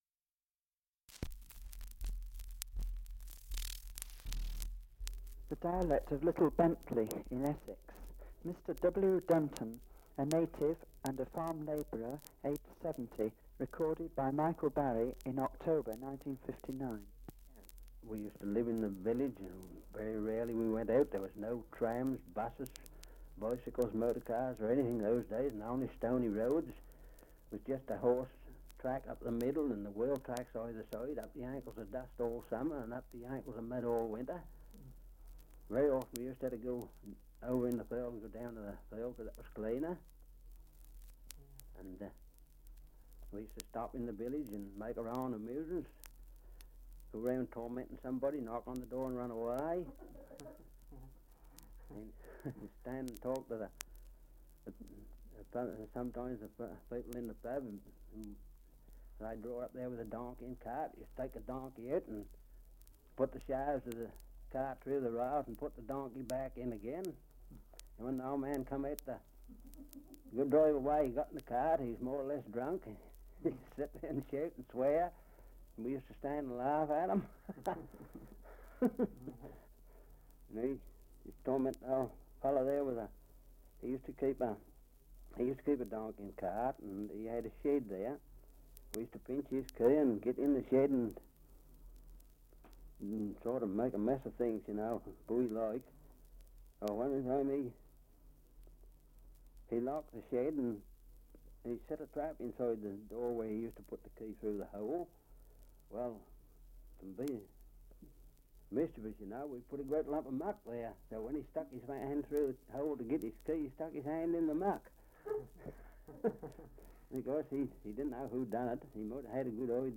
Survey of English Dialects recording in Little Bentley, Essex
78 r.p.m., cellulose nitrate on aluminium